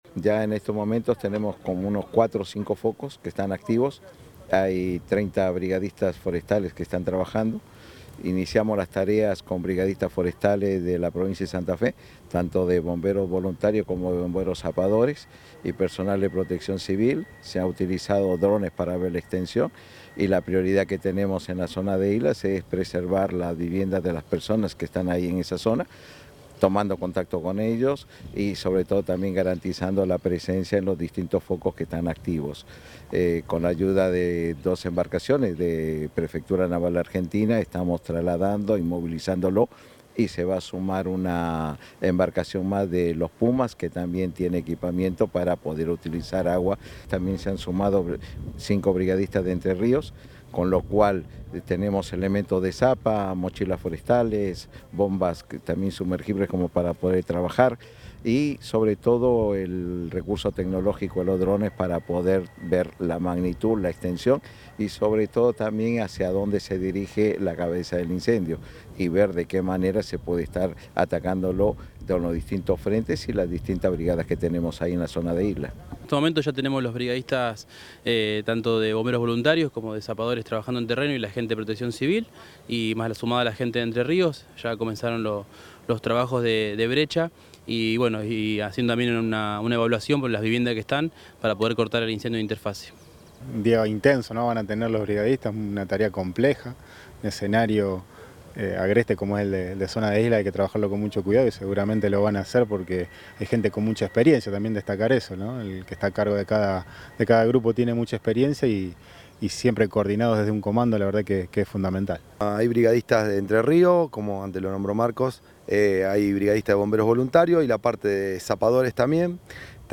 Ante esto, el secretario de Protección Civil, Marcos Escajadillo explicó que “hay entre cuatro y cinco focos que están activos y ya tenemos 30 brigadistas forestales trabajando. Iniciamos las tareas con brigadistas forestales de la provincia de Santa Fe, tanto de bomberos voluntarios como de bomberos zapadores y personal de protección civil”.
Declaraciones Escajadillo